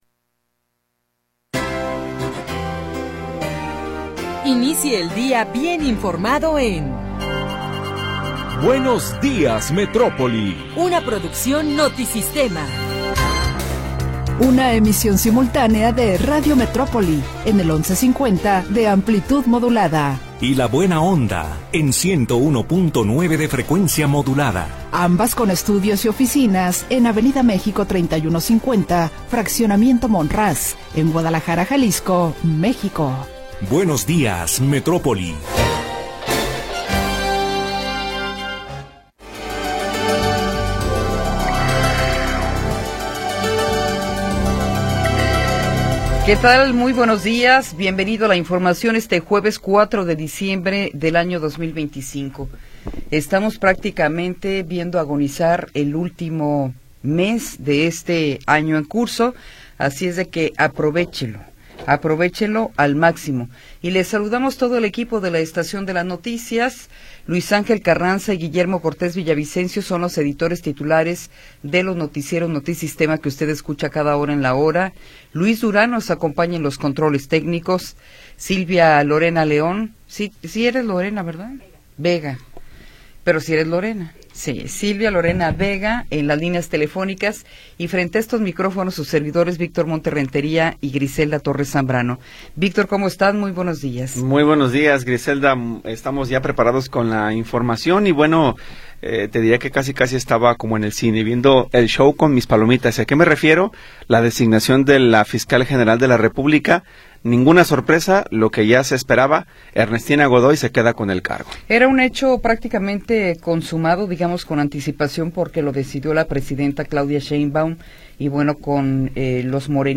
Primera hora del programa transmitido el 4 de Diciembre de 2025.